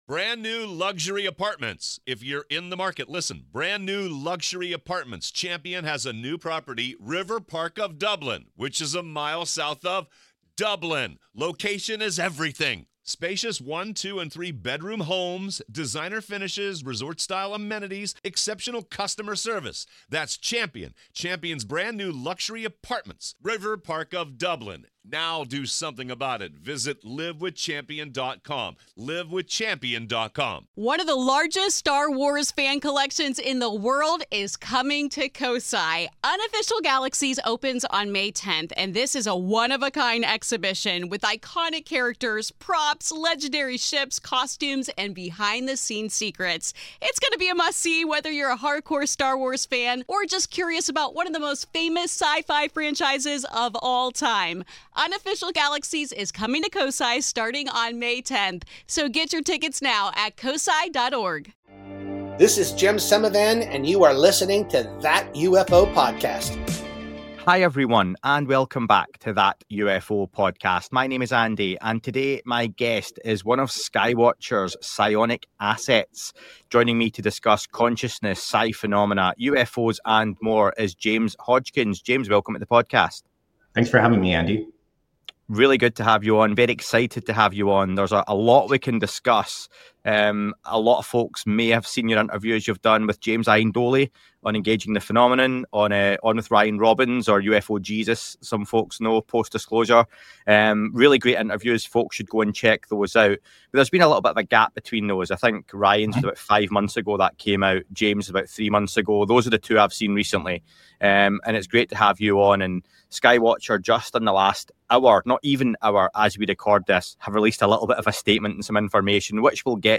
Exploring Psionic Phenomena & UFOs: An In-Depth Conversation